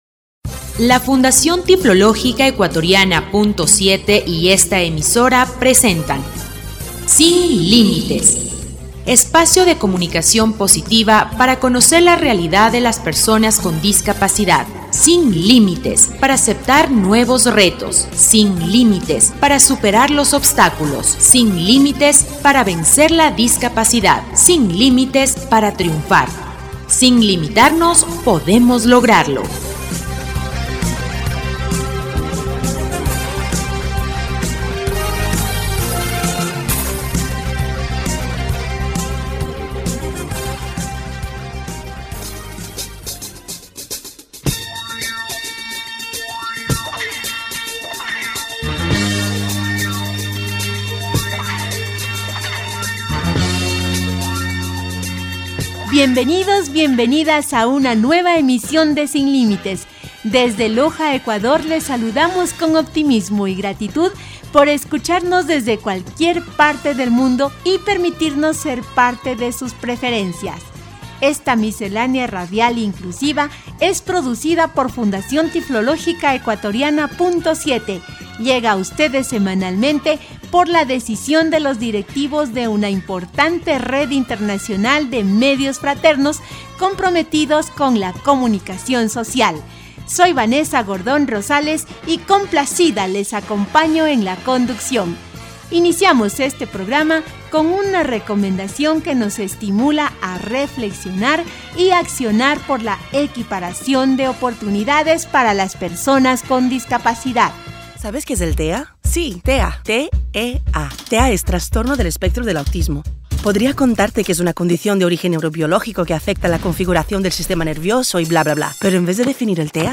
Programa radial «Sin Límites» 1369